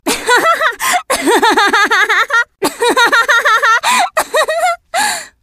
Play Amy Rose Laugh - SoundBoardGuy
amy-rose-laugh.mp3